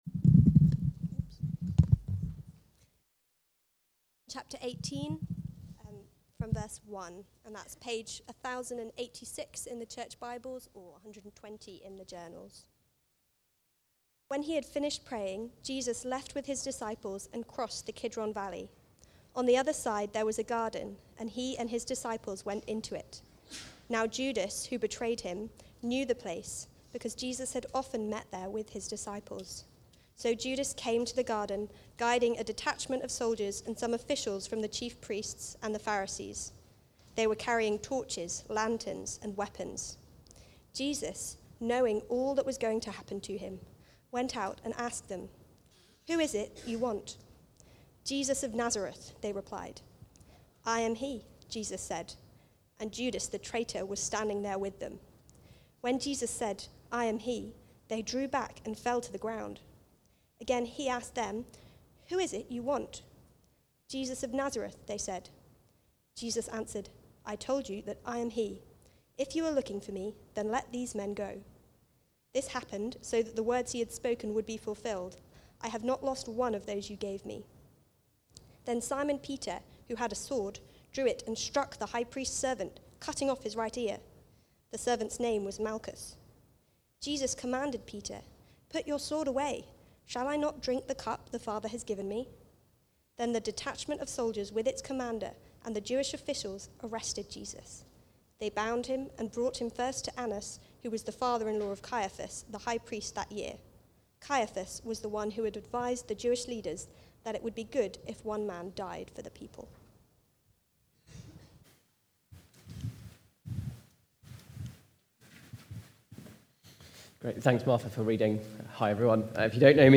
The Arrest (John 18:1-14) from the series Life From Death. Recorded at Woodstock Road Baptist Church on 15 February 2026.